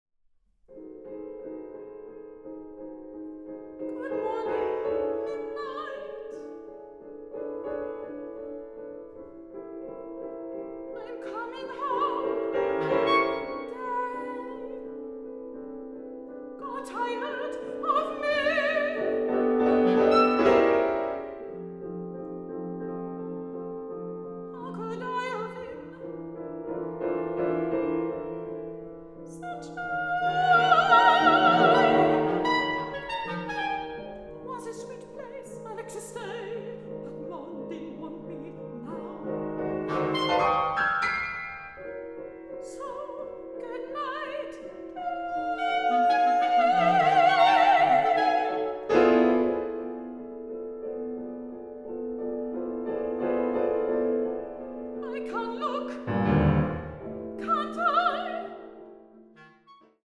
Sopran
Klarinette
Klavier